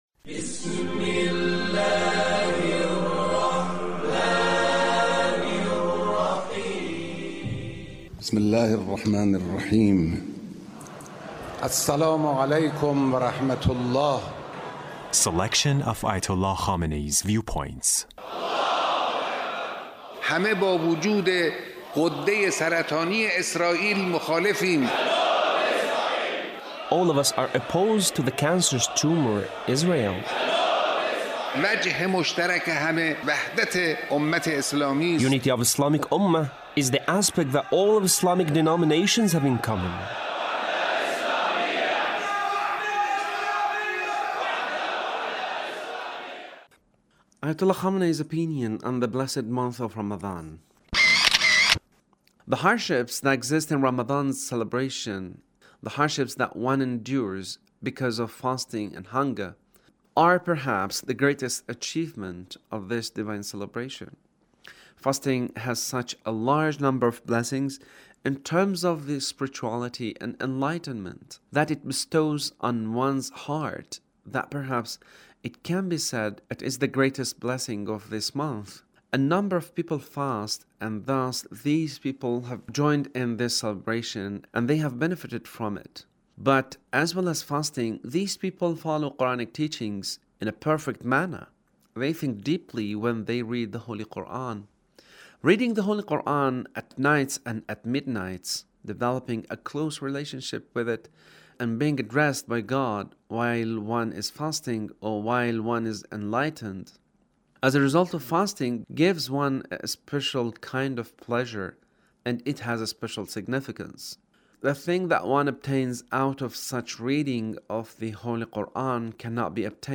Leader's Speech on Norooz 1402